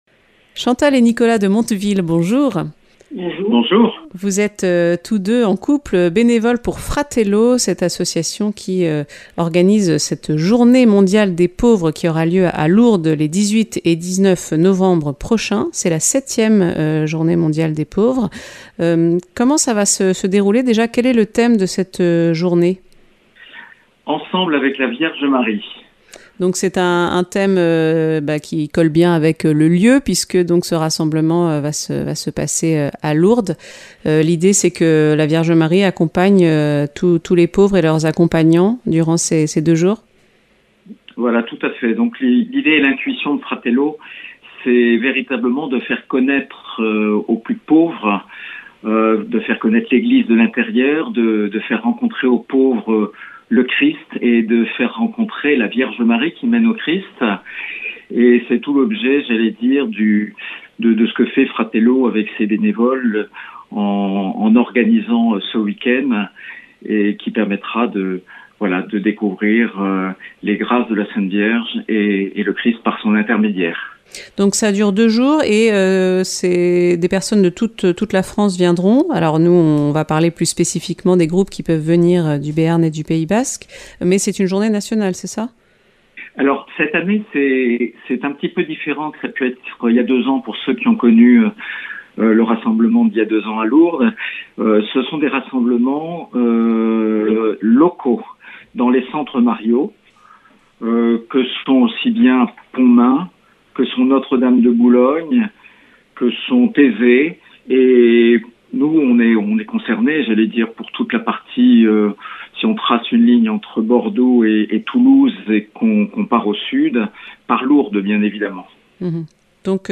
Accueil \ Emissions \ Infos \ Interviews et reportages \ 7ème Journée mondiale des pauvres avec Fratello à Lourdes les 18 et 19 (...)